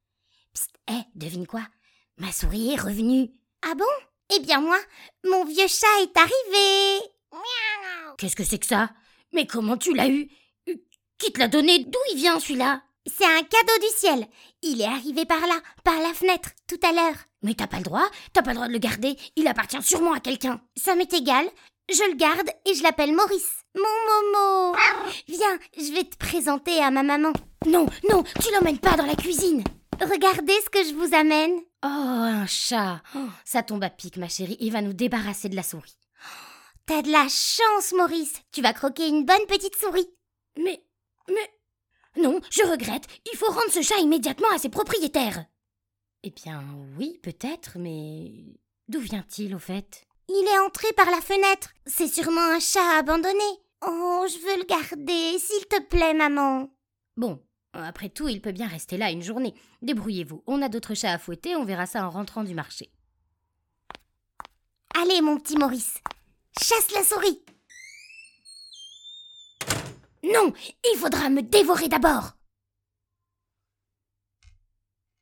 dialogue enfant fiction - toutes les voix
5 - 35 ans - Mezzo-soprano